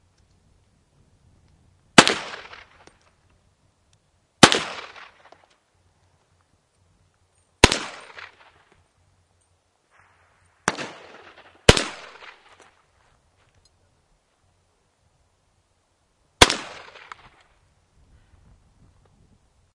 M1卡宾枪01
描述：拍摄二战时期的M1卡宾枪。用Rode麦克风和Canon HF20摄录机录制。
Tag: 录音 麦克风 步枪 M1 30 卡宾枪 二战 子弹 口径